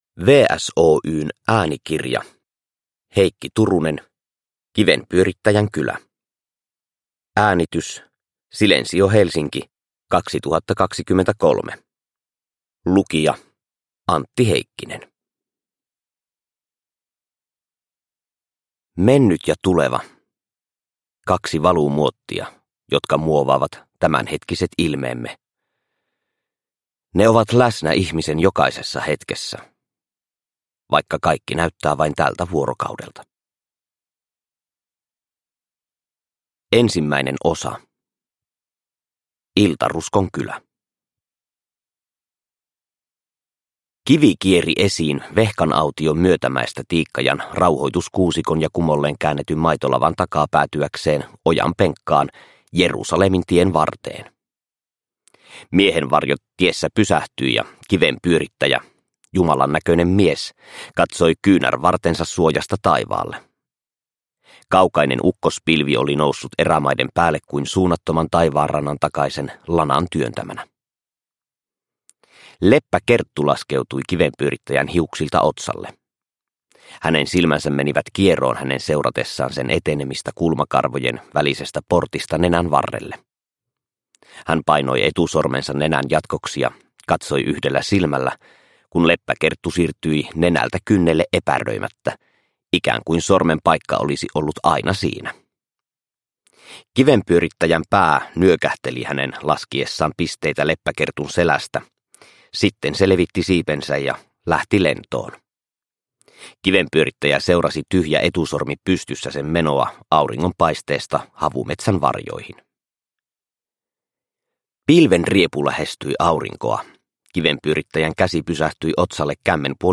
Kivenpyörittäjän kylä – Ljudbok – Laddas ner